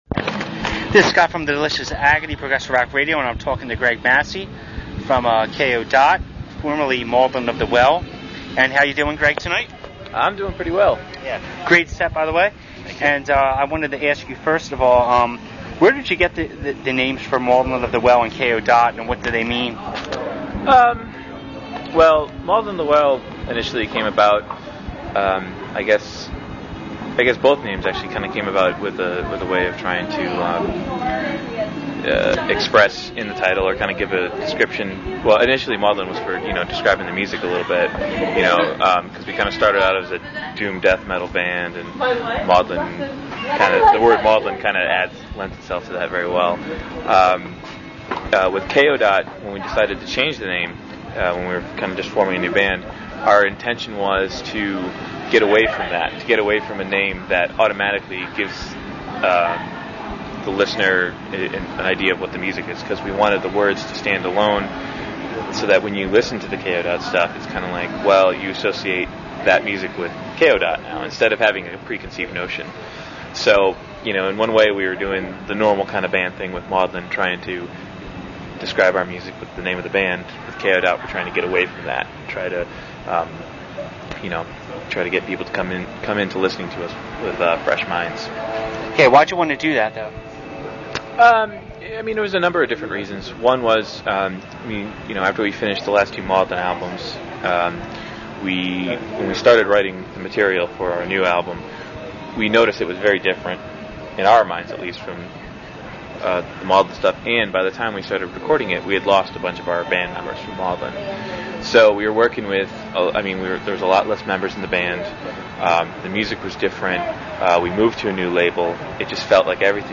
Kayo Dot Delicious Agony Interview.mp3